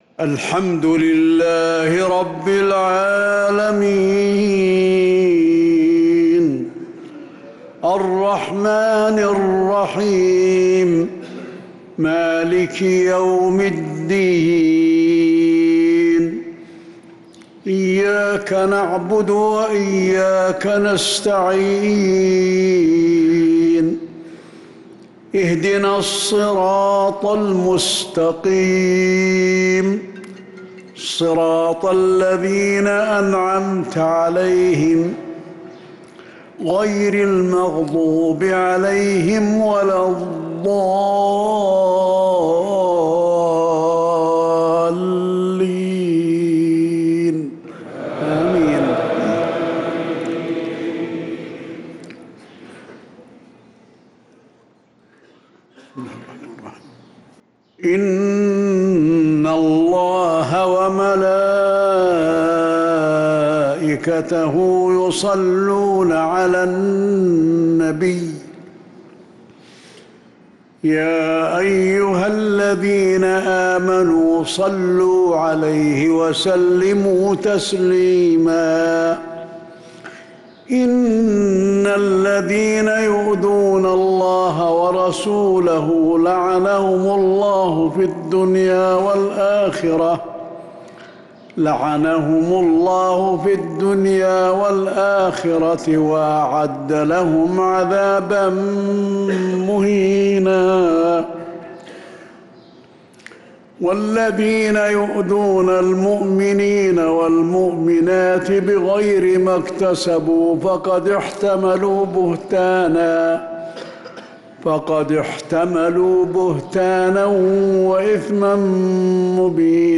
مغرب الخميس 6-9-1446هـ من سورة الأحزاب 56-71 | Maghrib prayer from Surat al-Ahzab 6-3-2025 > 1446 🕌 > الفروض - تلاوات الحرمين